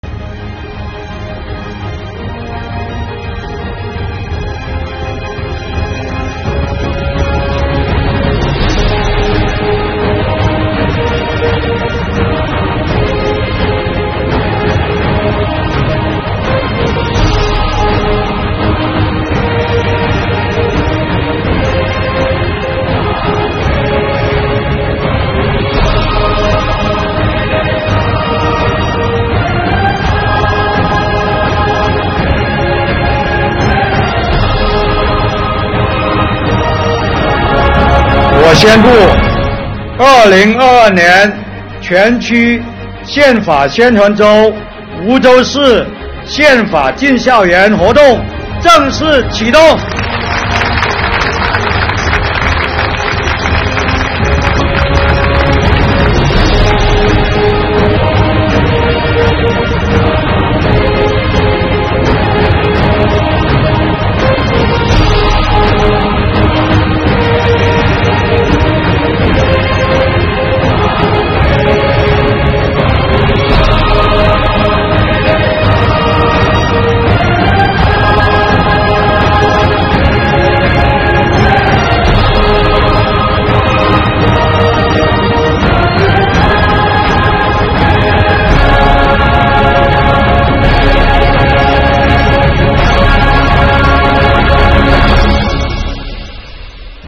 表演精彩纷呈，现场掌声不断。
2022年全区“宪法宣传周”梧州市“宪法进校园”活动现场